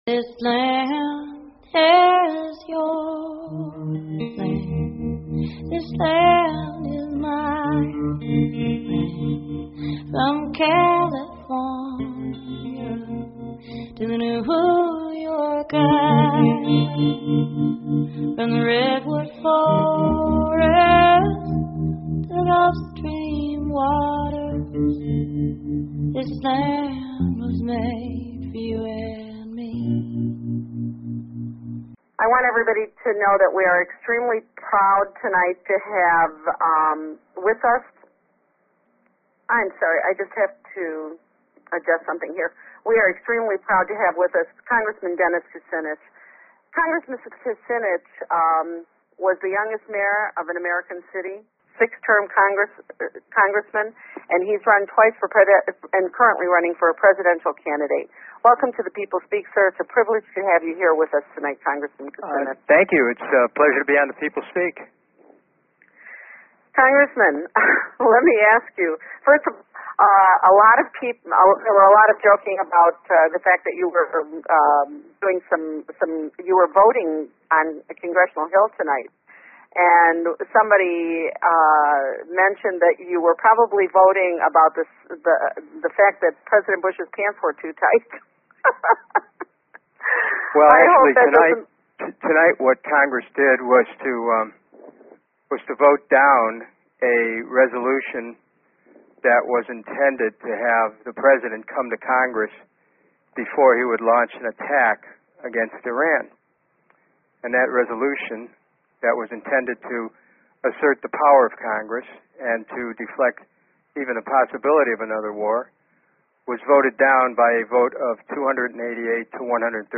Guest, Dennis Kucinich